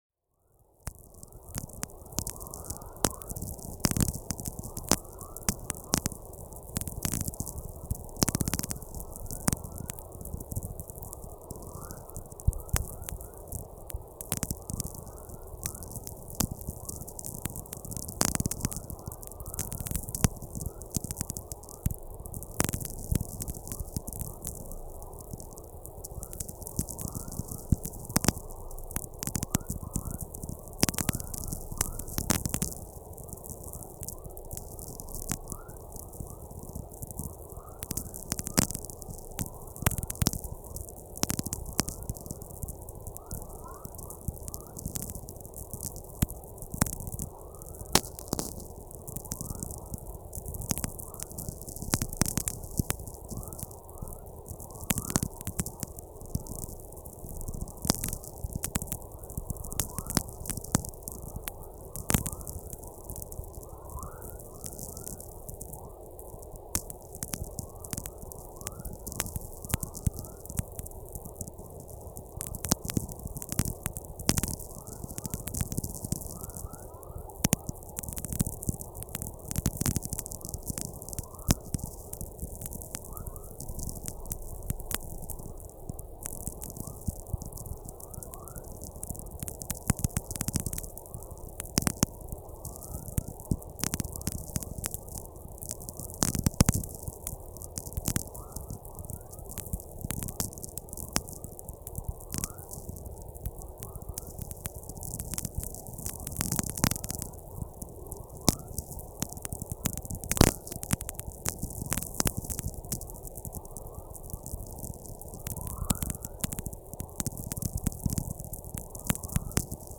Not least the space weather, i.e. solar storms, because in the coming years solar activity will increase, which could well be my last chance to record something like this. Last winter I built both a VLF receiver and a good antenna that was and is easy for me to set up alone in almost any weather.
I try to avoid being close to high mountains or buildings that block the view into space, or cause electrical pollution.
Almost without exception, you can expect to hear „Dawn chorus“ and „Whistlers“. in all sorts of varying pitches.